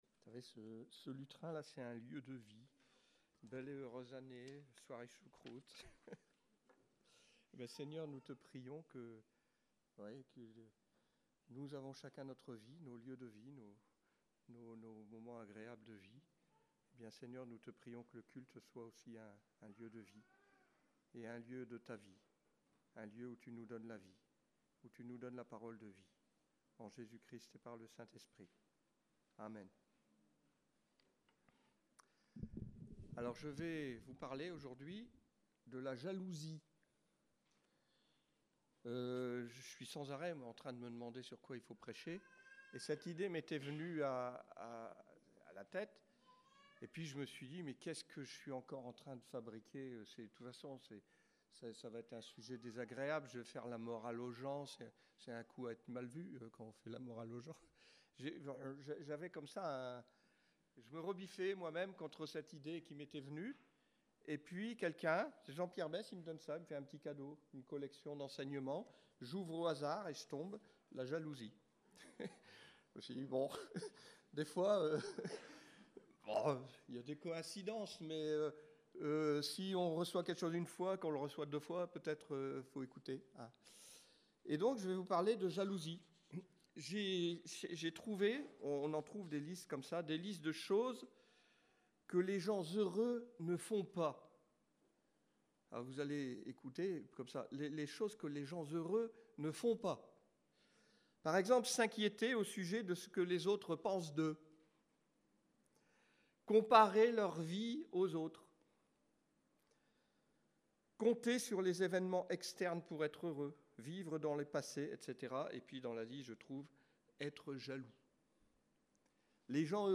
Prédications La jalousie